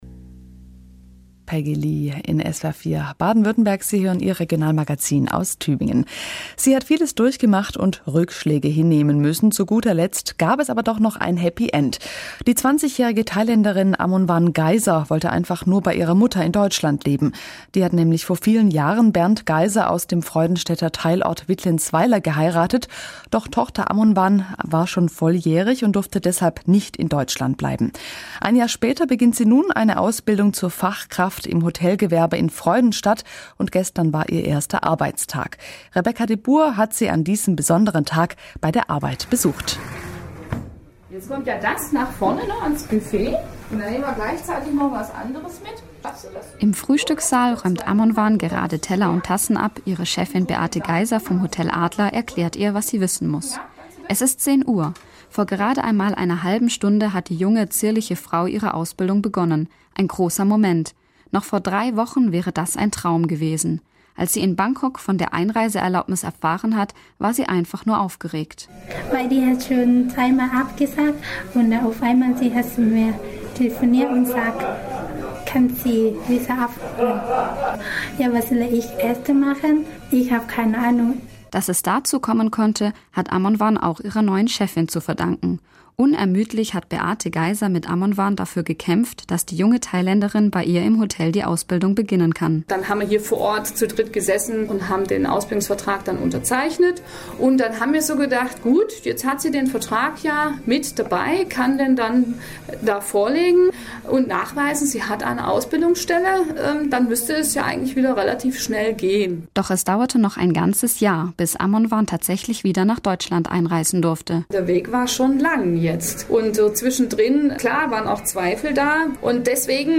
Ui, also grundsätzlich mal: mit mehr Elan sprechen! Ich wirke unglaublich traurig und gewollt seriös.
Ich würde definitiv dafür sorgen, dass bei der O-Ton-Aufnahme hinten kein Radio läuft und ich das Mikro näher am O-Ton-Geber halte!